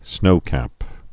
(snōkăp)